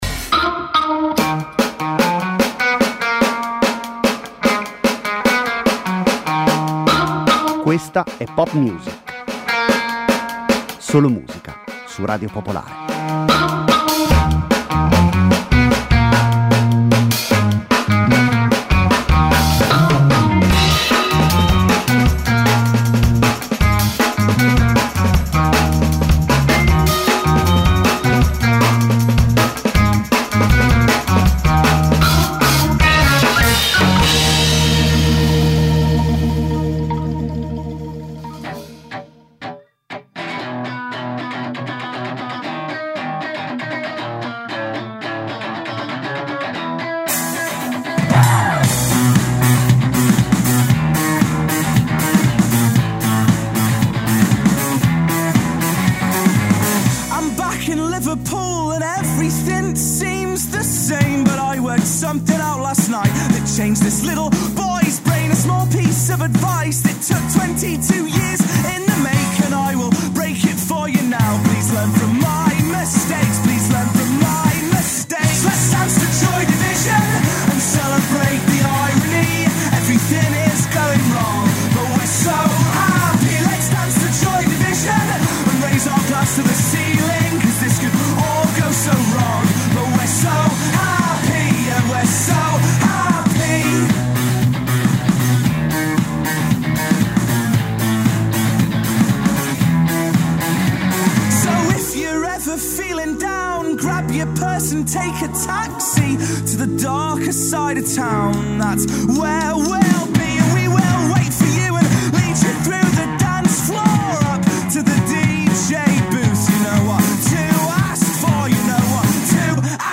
Una trasmissione di musica, senza confini e senza barriere.
Senza conduttori, senza didascalie: solo e soltanto musica.